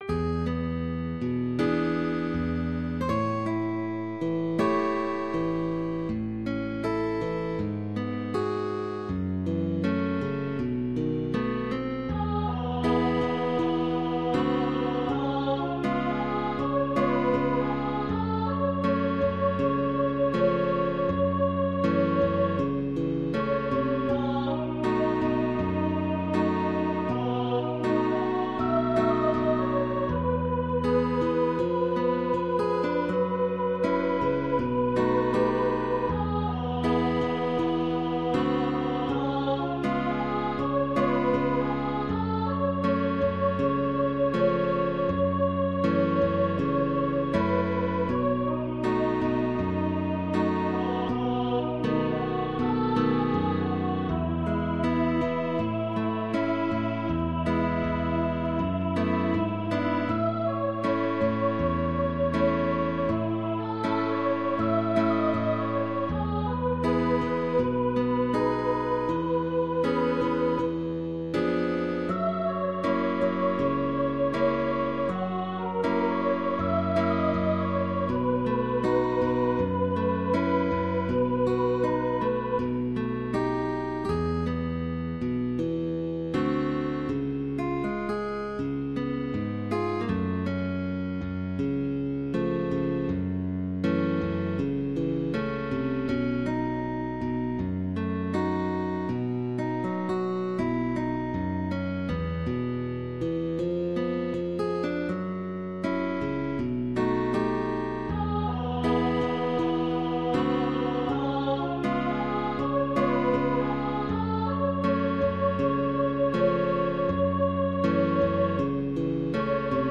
balada